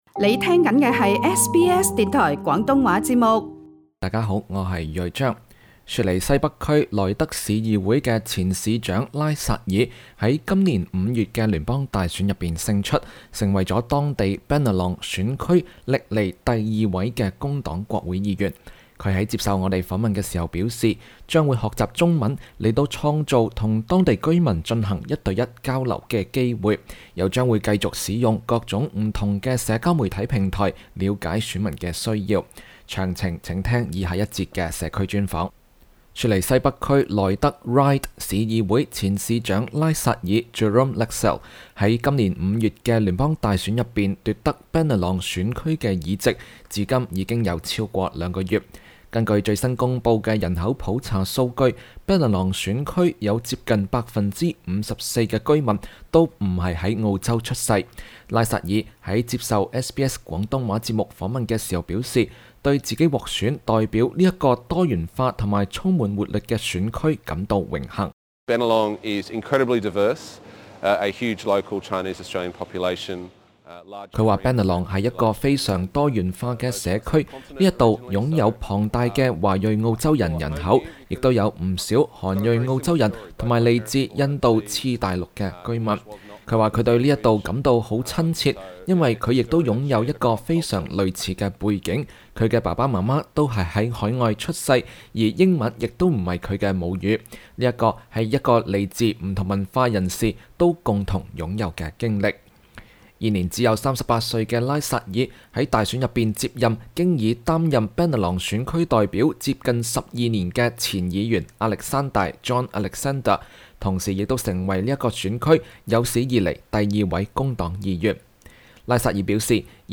新州 Bennelong 選區的新任國會議員拉薩爾（Jerome Laxale）於雪梨西北區 Eastwood 接受 SBS 廣東話節目訪問。